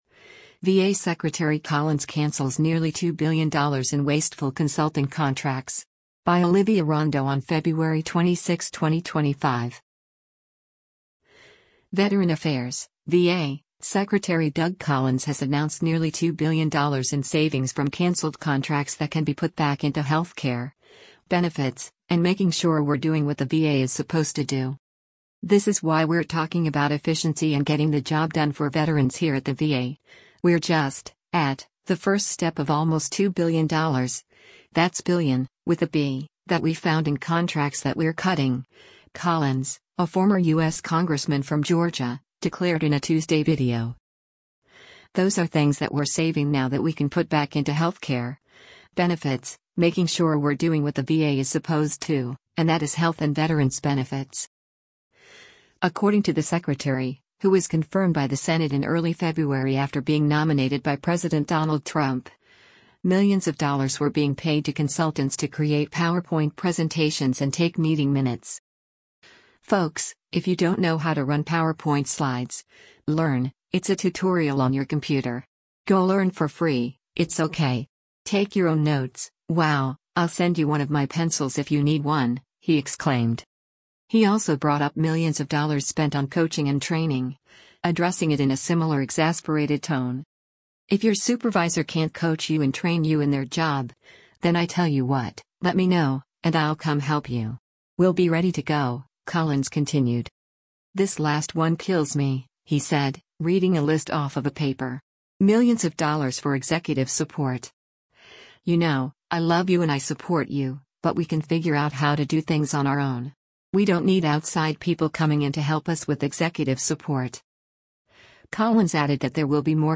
He also brought up millions of dollars spent on “coaching and training,” addressing it in a similar exasperated tone.